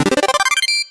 level_up.ogg